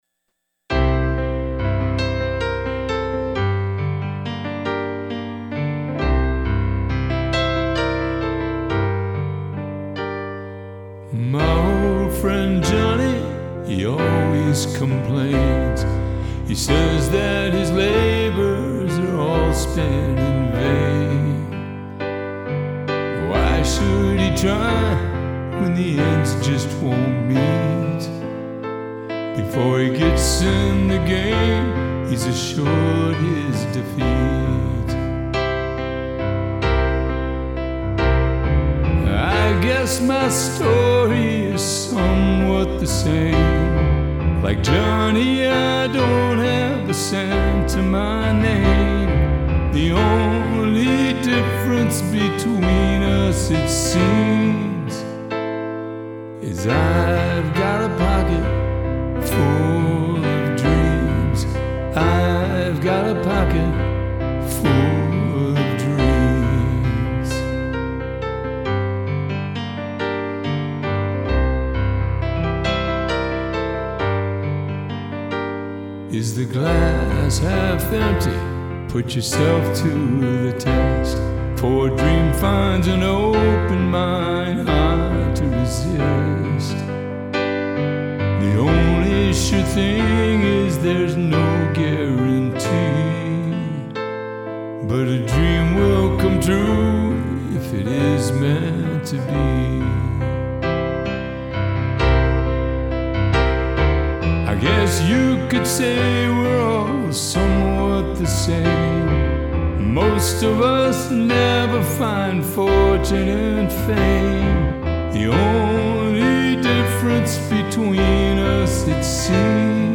Our Fall Coffeehouse was on November 22nd.
audio recording of me performing it.